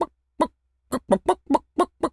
chicken_cluck_bwak_seq_05.wav